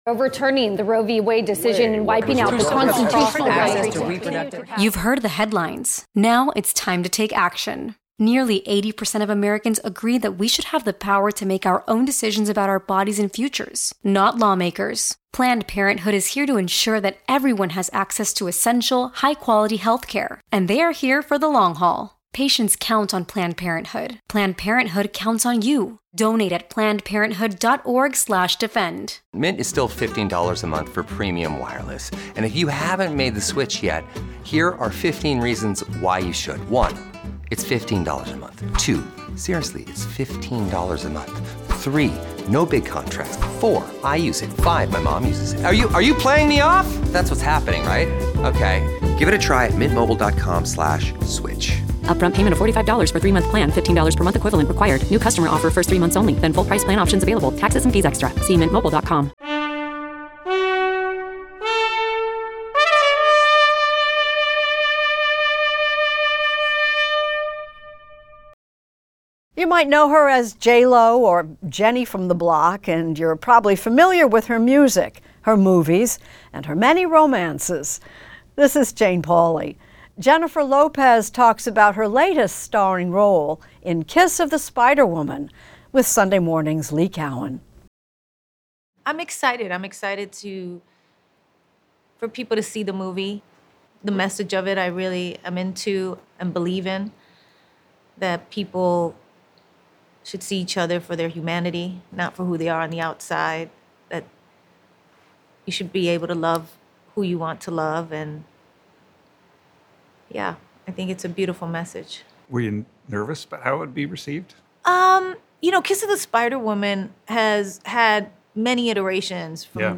Extended Interview: Jennifer Lopez